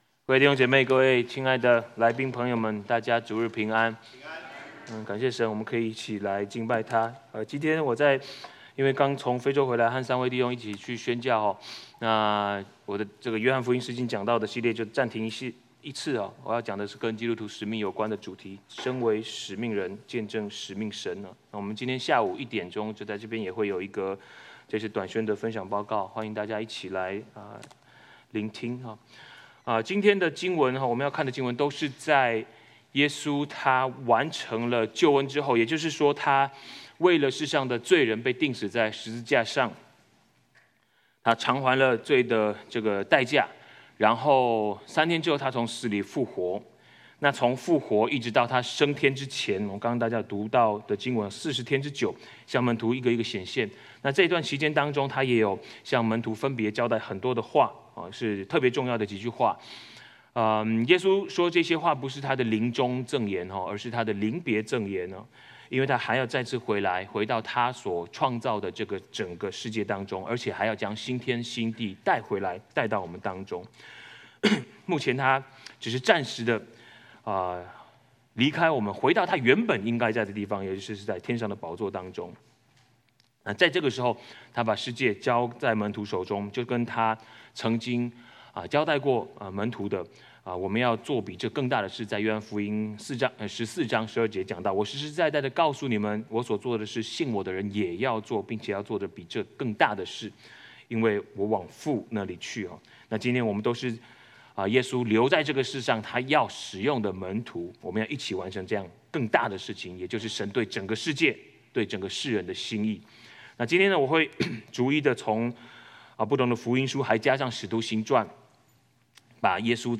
2025 主日證道 Passage: 馬太福音 28:16-20；馬可福音 16:14-20；路加福音 24:44-49；約翰福音 20:19-23；使徒行傳 1:6-11 Service Type: 主日證道 Download Files Notes « 約書亞記 1-10 約書亞記 11-20 »